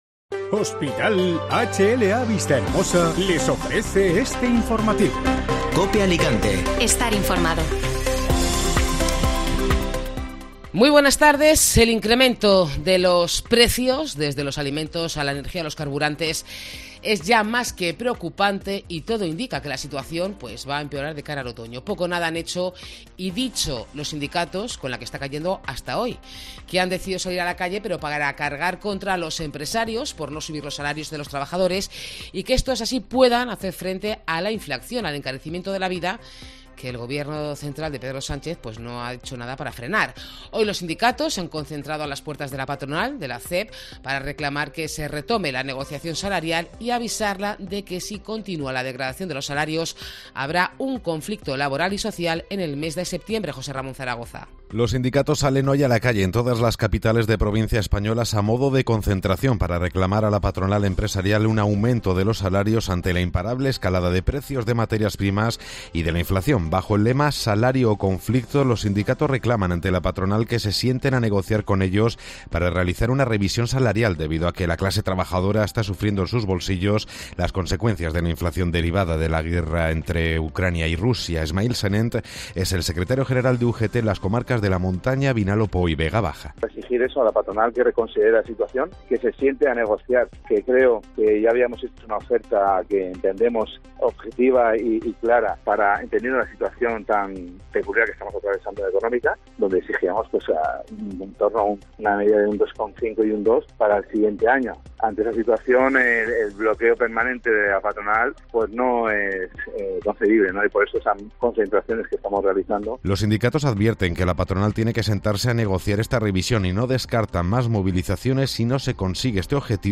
informativo Mediodía Cope (Miércoles 6 de Julio)